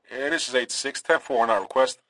Tag: 喋喋不休 警察 收音机